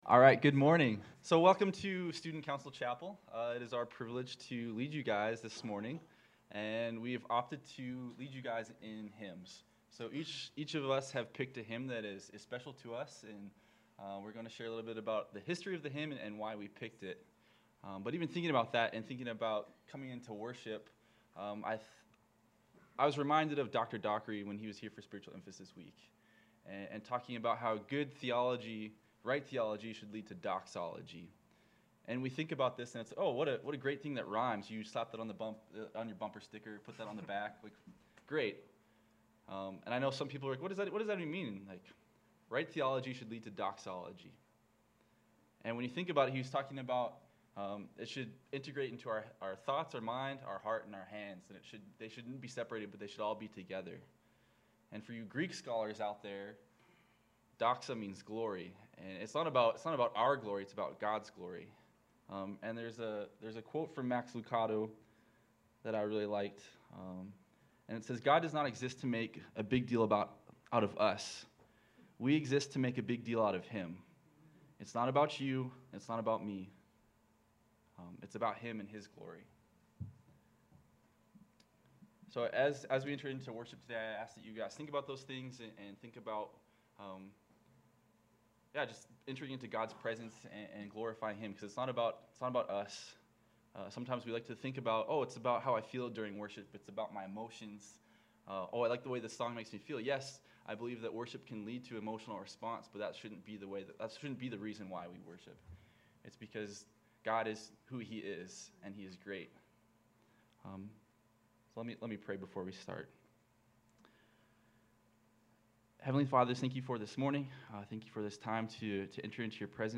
| CSBS&C Student Council leads in worship.